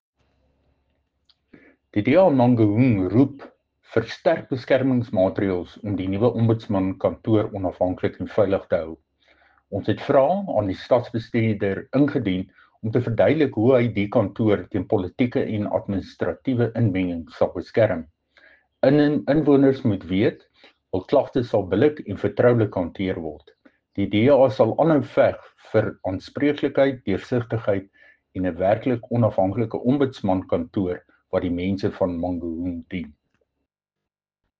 Afrikaans soundbites by Cllr Dirk Kotze and Sesotho soundbite by Jafta Mokoena MPL.